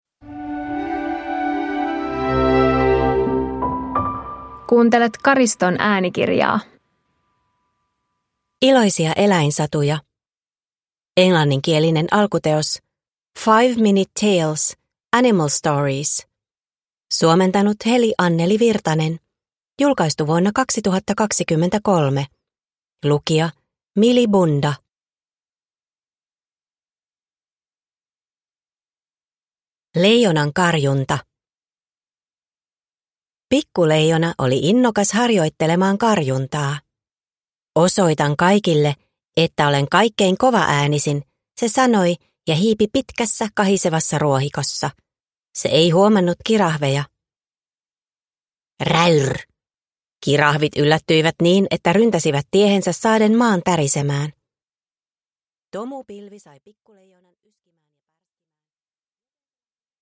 Iloisia eläinsatuja – Ljudbok